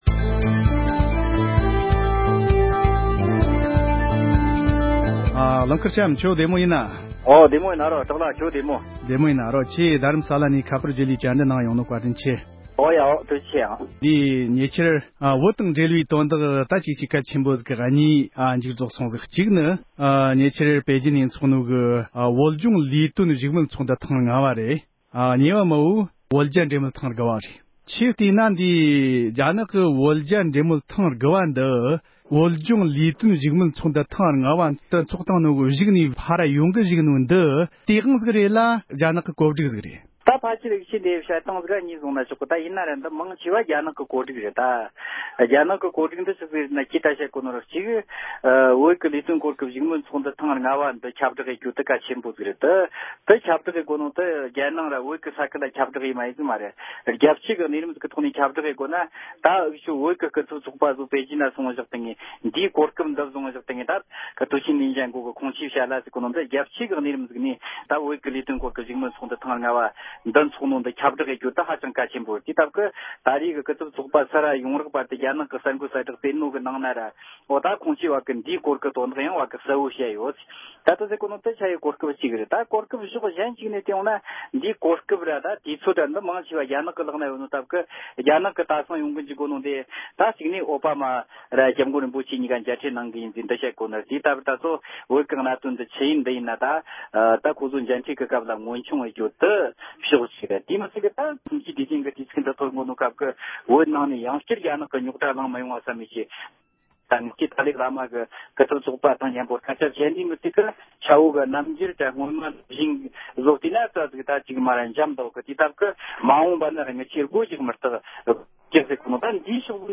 བོད་ཕྱོགས་ནས་ད་བར་དུ་བོད་དོན་ཐག་གཅོད་བྱེད་རྒྱུའི་ཐབས་ལམ་ཞིག་རྙེད་ཡོད་མེད་ཐད་བགྲོ་གླེང༌།